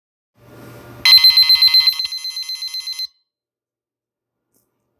○本鈴